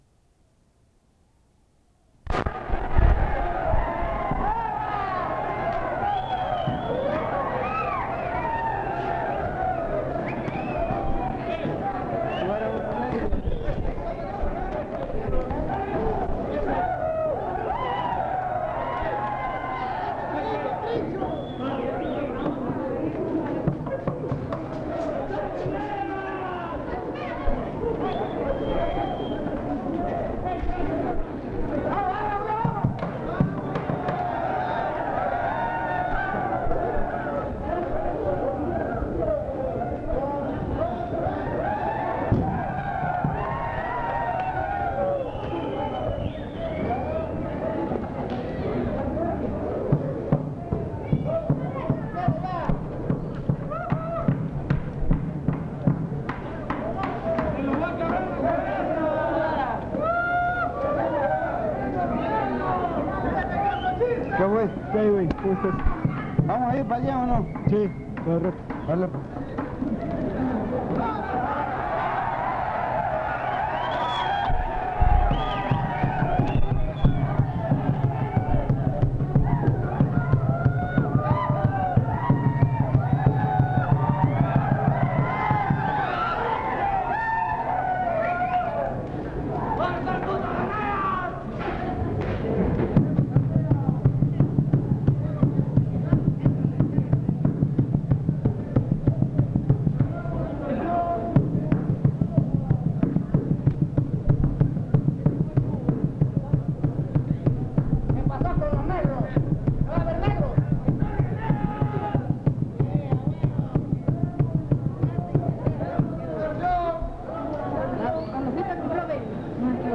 Crónica
Fiesta del Señor Santiago